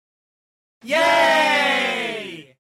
groupYay.mp3